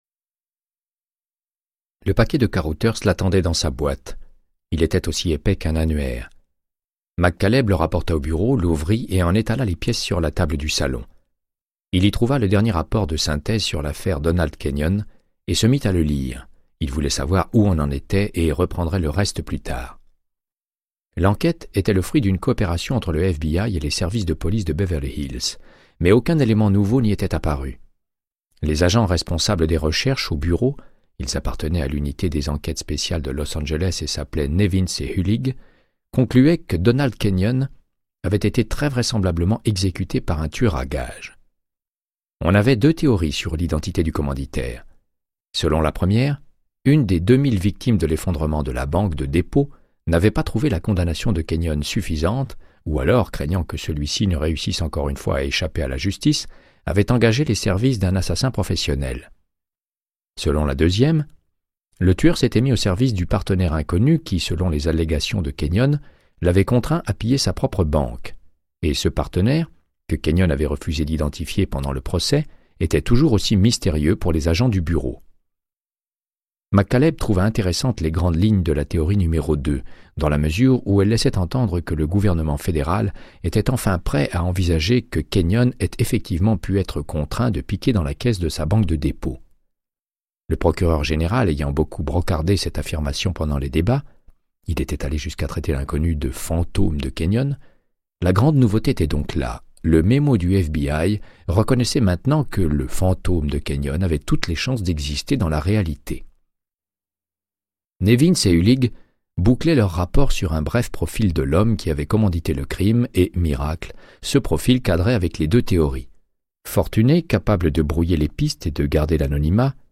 Audiobook = Créance de sang, de Michael Connellly - 101